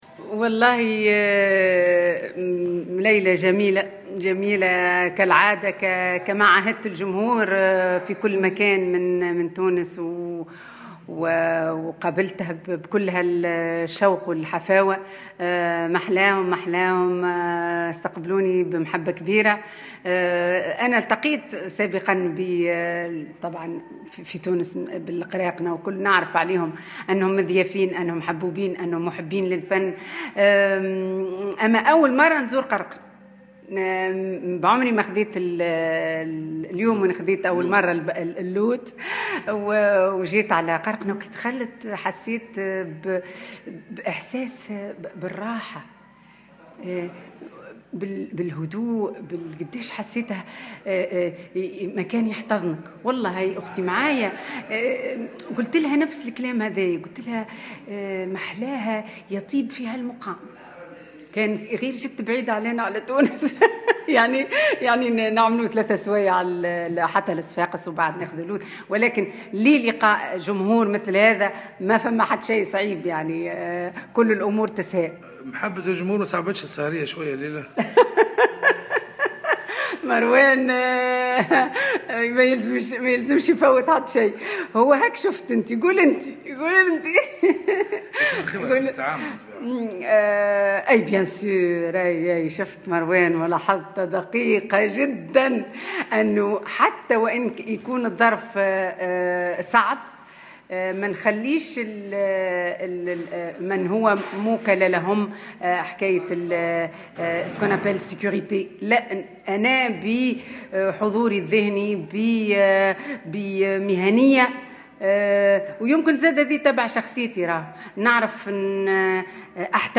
حفل إختتام مهرجان ليالي قرقنة (صور وتصريحات)